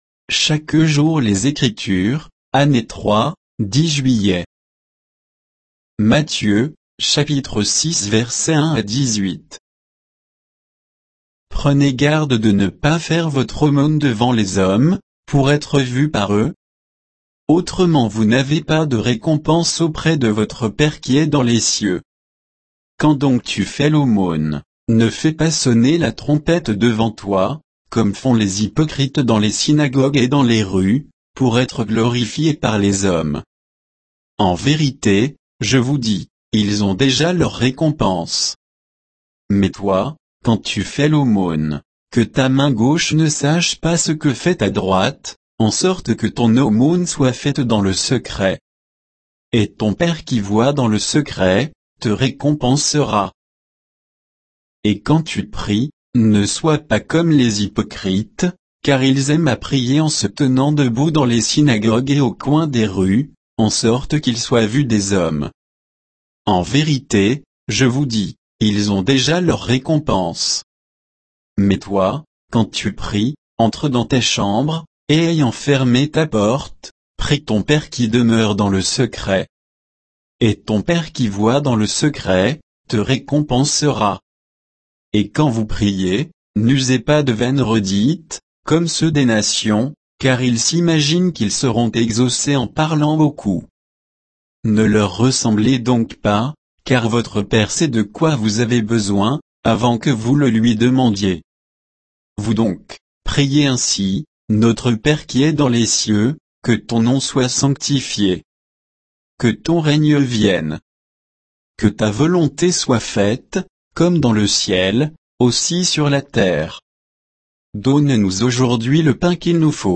Méditation quoditienne de Chaque jour les Écritures sur Matthieu 6, 1 à 18